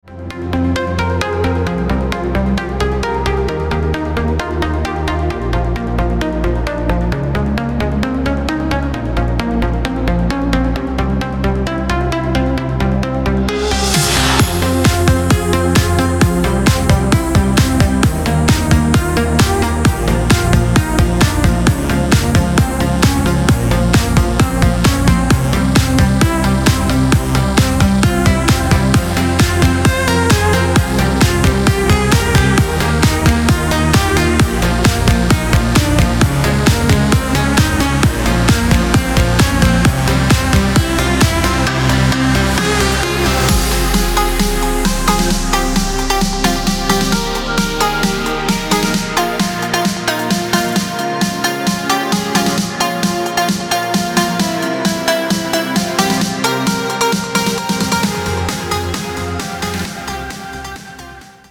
• Качество: 256, Stereo
house